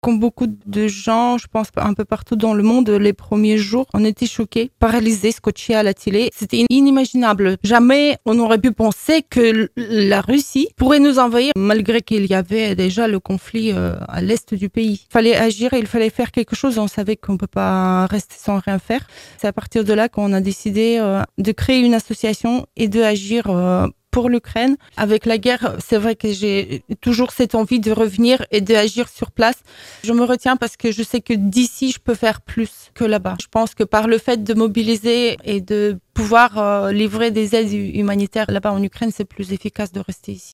Elle a accepté de témoigner au micro La Radio Plus